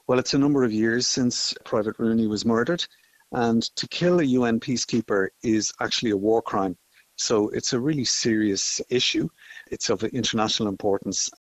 Security Analyst Senator Tom Clonan says it is taking a long time: